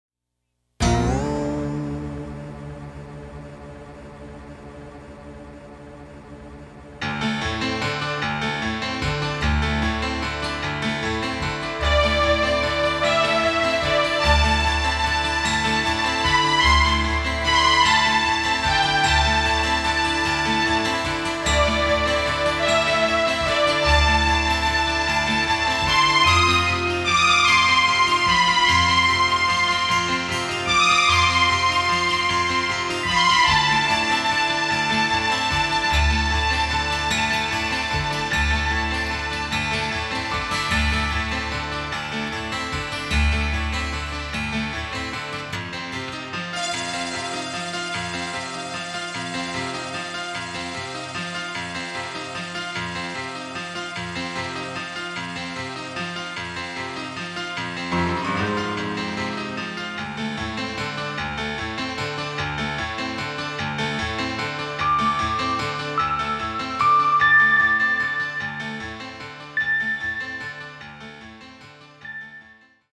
original motion picture soundtrack
electronic music studio